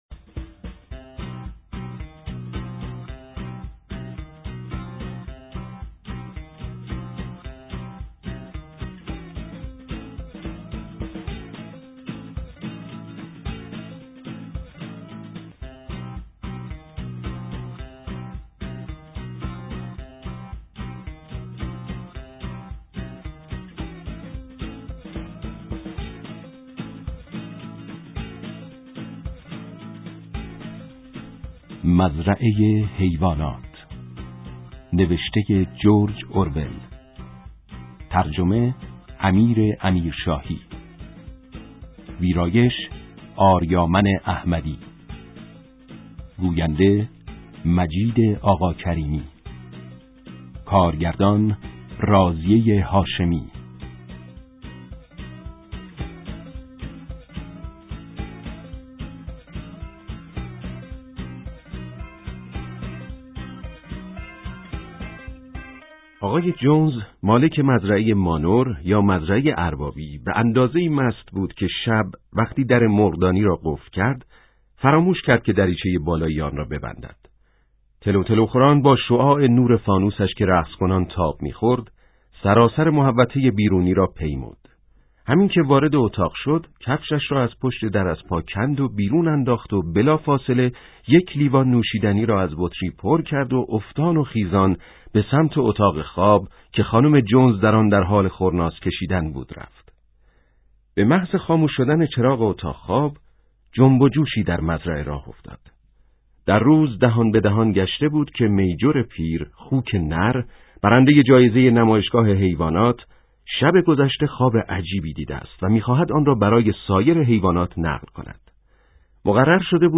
کتاب صوتی قلعه حیوانات اثر جورج اورول قسمت 1
کتاب صوتی قلعه حیوانات (نسخه نمایشی) اثر جورج اورول قسمت 1 📖توضیحات: